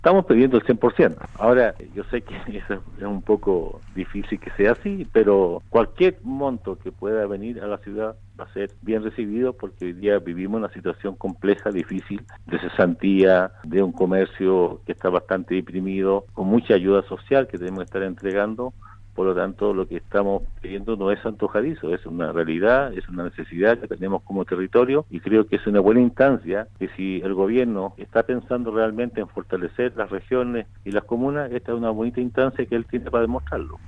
En conversación con Radio Sago, el alcalde de Osorno, Emeterio Carrillo, dio a conocer cuáles serán los primeros pasos en torno al trabajo municipal, tras asumir como jefe comunal durante esta semana.